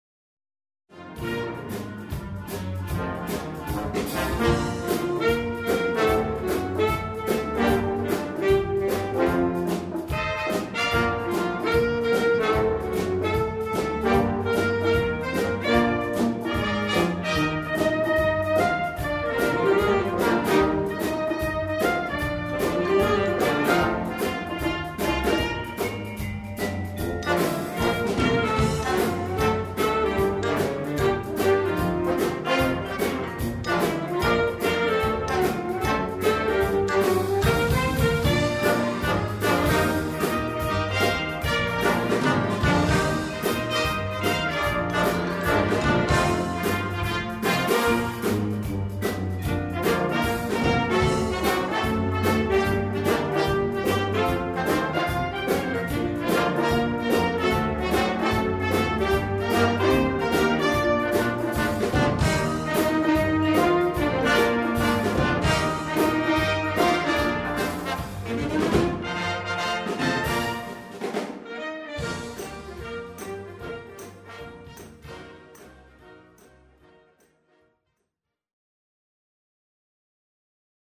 Recueil pour Harmonie/fanfare